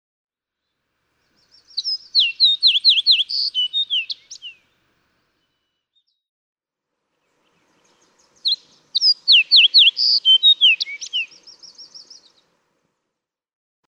Fox sparrow
♫288—one song from each of two individuals
288_Fox_Sparrow.mp3